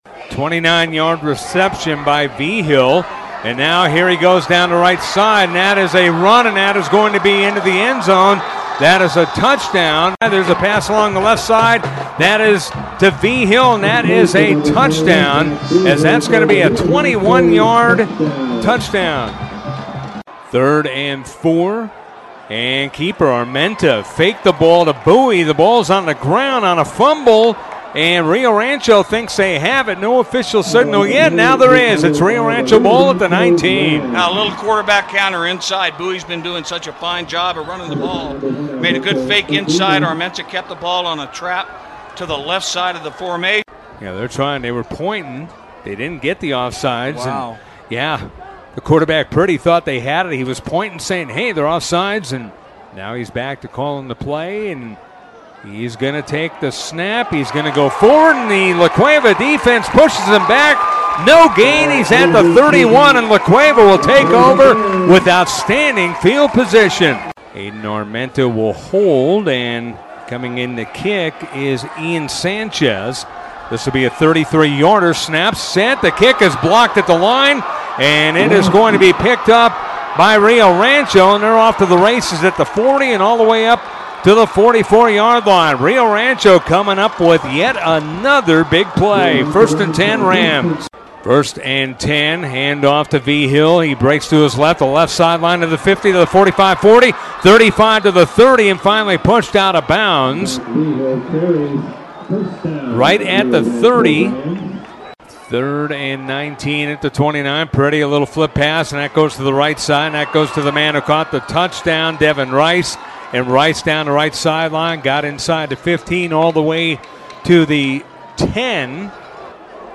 ESPN Radio 101.7 The TEAM High School Football Broadcasts